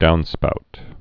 (dounspout)